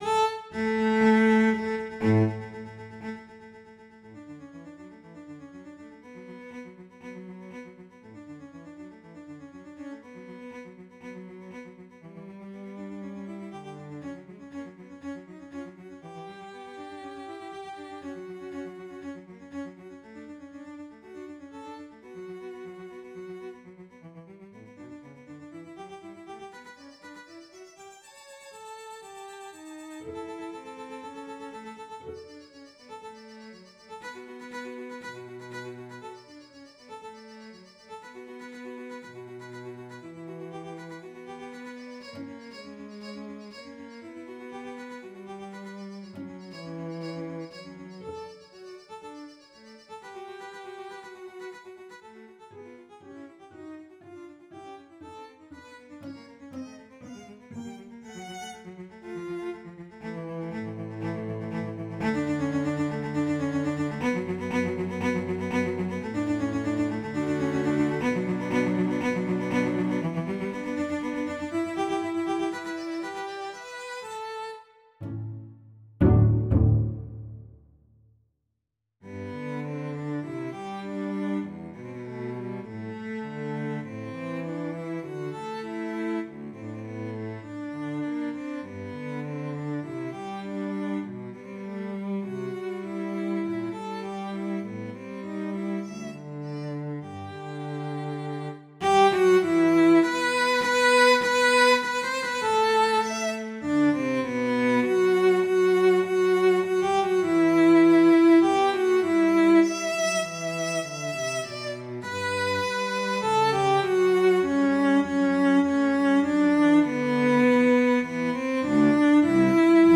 Parts: 2 cellos
Key signature: 2 sharps   to MuseScoreto pdf file